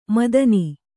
♪ madani